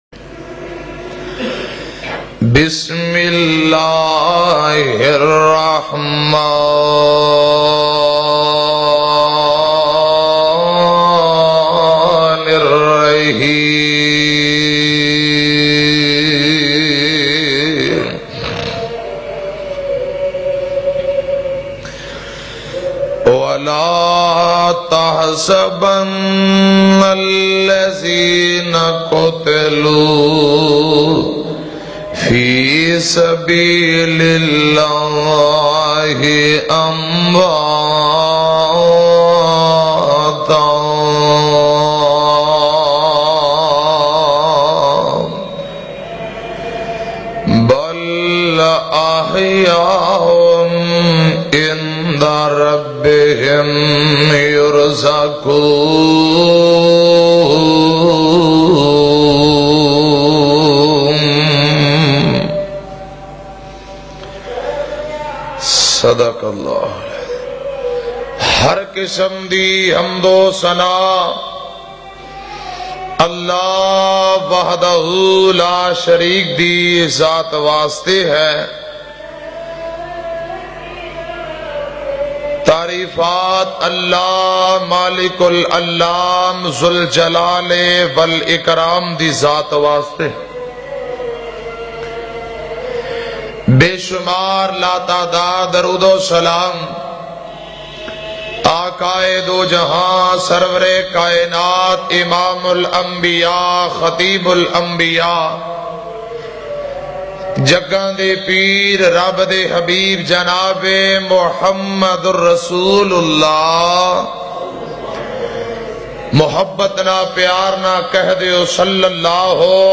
Hazrat umar farooq bayan